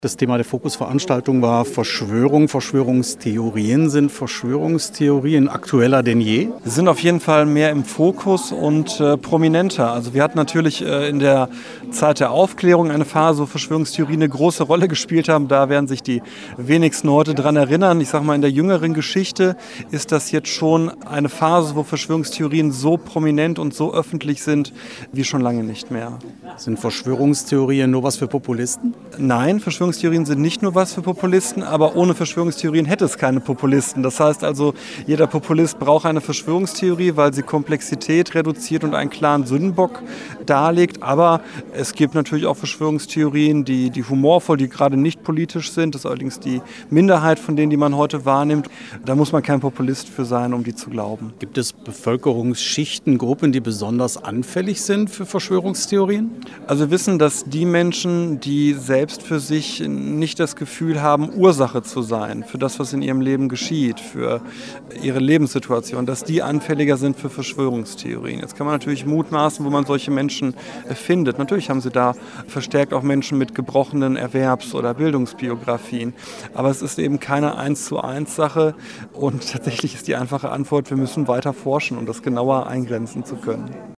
Am Dienstagabend fand im Europasaal des Ministeriums in Eupen eine Veranstaltung zum Thema „Verschwörungstheorien“ statt. Im Rahmen der Fokus-Reihe referierten drei hochkarätige Experten und diskutierten anschließend mit dem Publikum.